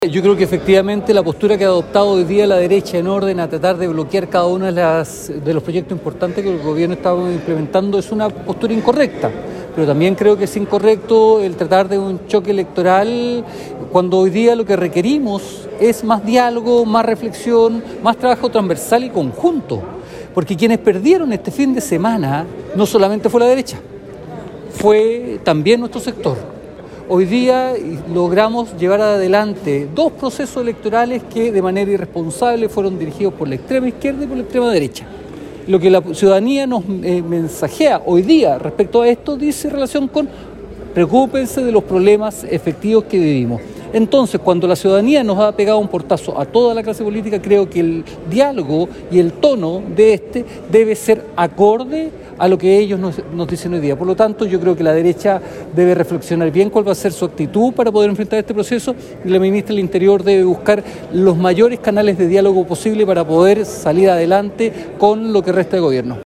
A dos días del Plebiscito que significó el triunfo del “en contra” sobre la propuesta constitucional presentada por los sectores conservadores de la política, el diputado PS, Marcos Ilabaca, se refirió a los dichos de la Ministra del Interior, Carolina Tohá, quien indicó que la oposición hoy carece de crítica con respecto al fallido proceso constitucional.